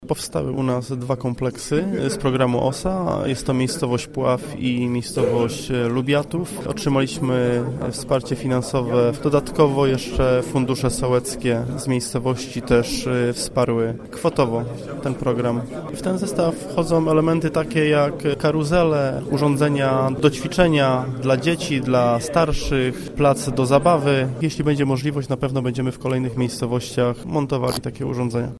– Otwarte Strefy Aktywności, które powstały w Lubiatowie i Pławiu – informuje Marcin Reczuch, wójt Dąbia.